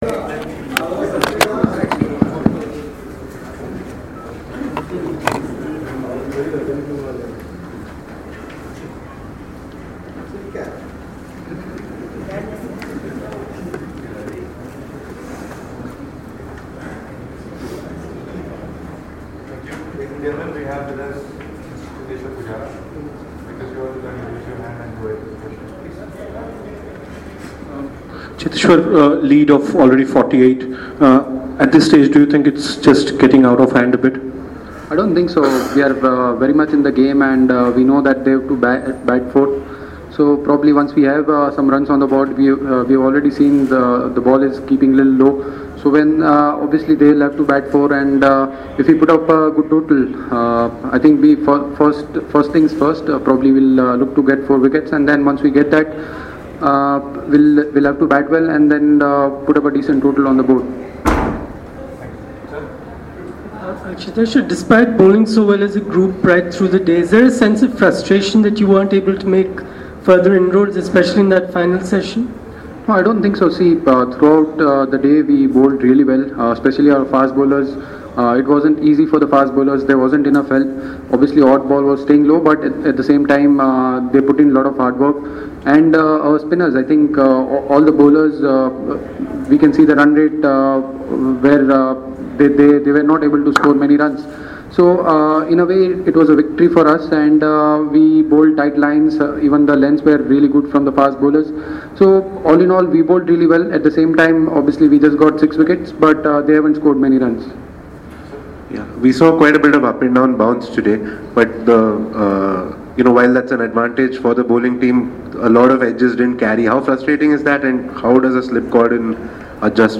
LISTEN: Pujara speaks after Day 2 of the Bengaluru Test